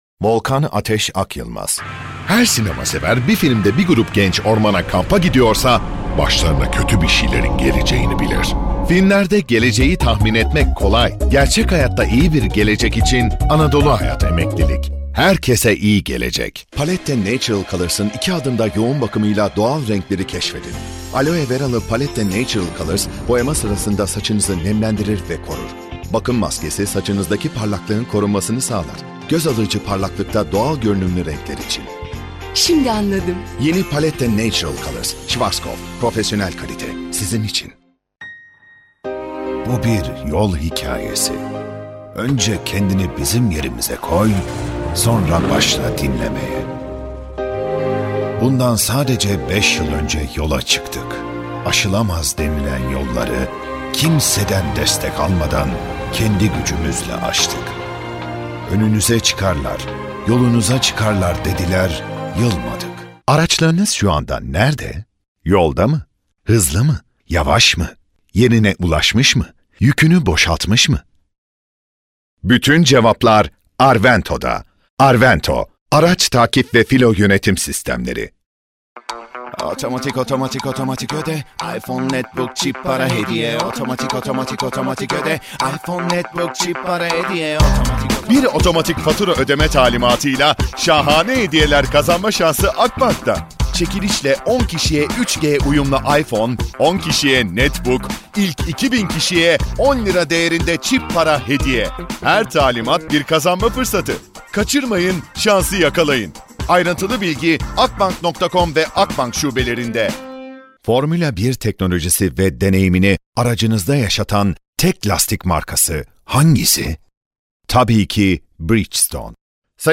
KATEGORİ Erkek
Belgesel, Canlı, Fragman, Güvenilir, Karakter, Karizmatik, Promosyon, Film Sesi, Parlak, Tok / Kalın, Dış Ses, Olgun,